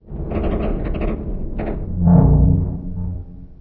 boilerGroan2.ogg